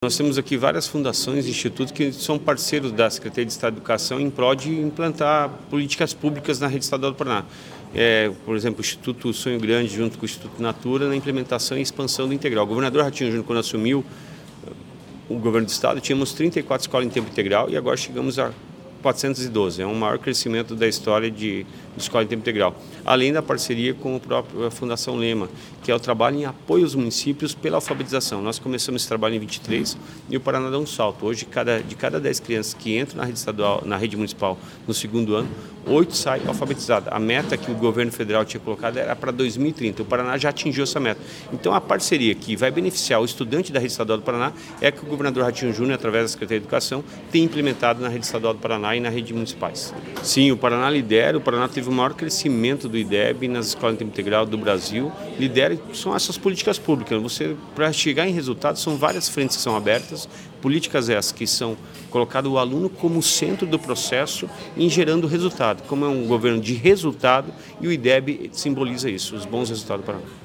Sonora do secretário da Educação, Roni Miranda, sobre a reunião para reforçar parceria com institutos para avançar na educação